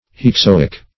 hexoic - definition of hexoic - synonyms, pronunciation, spelling from Free Dictionary Search Result for " hexoic" : The Collaborative International Dictionary of English v.0.48: Hexoic \Hex*o"ic\, a. (Chem.) Pertaining to, or derived from, hexane; as, hexoic acid.